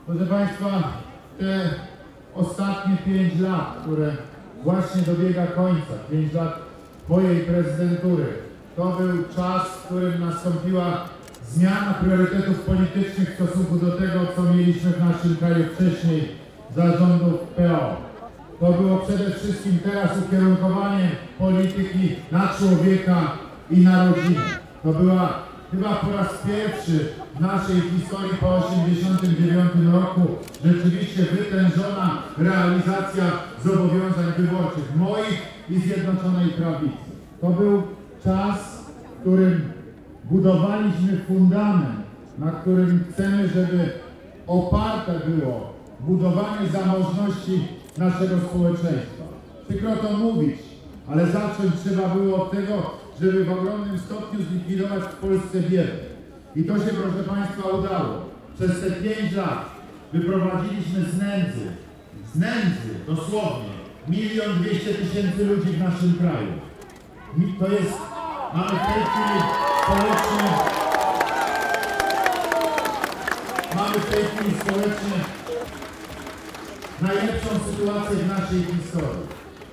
Na spotkanie w Parku Konstytucji 3 Maja przybyło wiele osób, także z regionu.